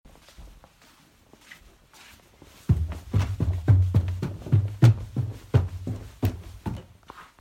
上楼梯
描述：攀爬楼梯。用iPhone 7修复。
Tag: 楼梯 木头